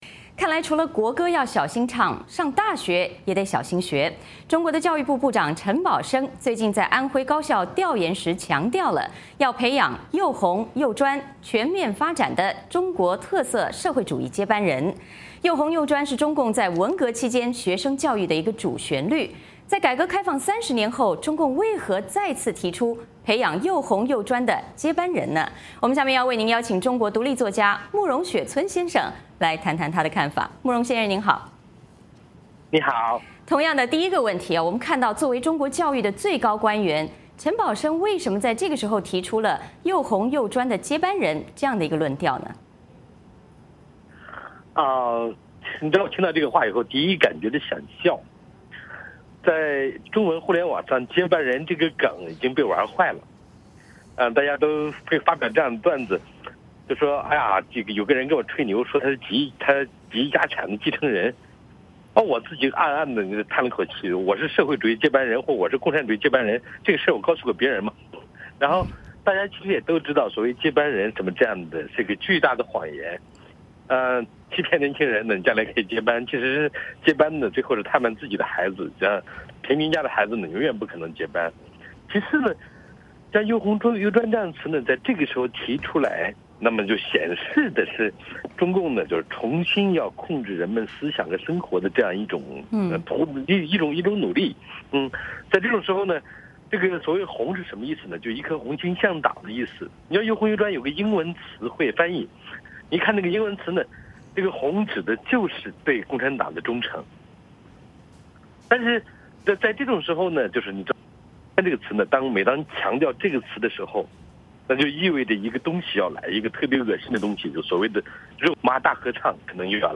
在改革开放三十年后，中共为何再次提出培养“又红又专”的接班人？接下来我们邀请中国独立作家慕容雪村来分析。